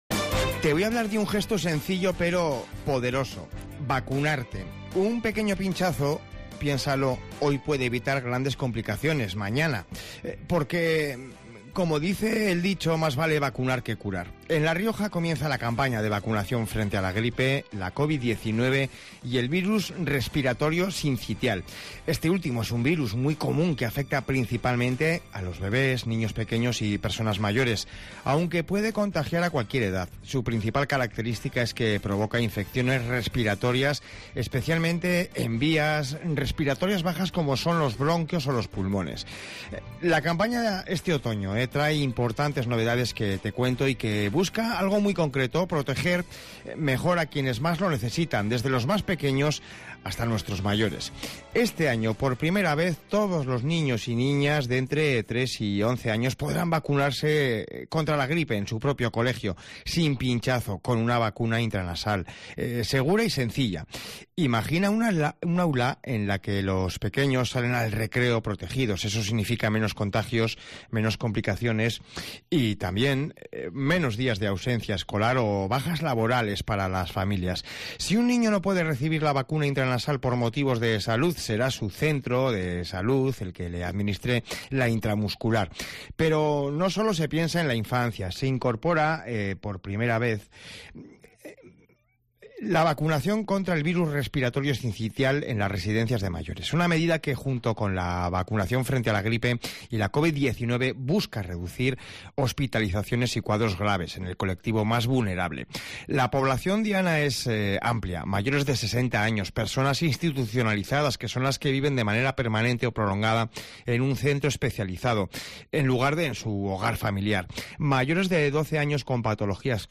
En la calle, muchos ciudadanos expresan su confianza en la vacunación como medida preventiva, tal como afirma un riojano, "llevo un montón de años y no he cogido ningún catarro, ni gripe".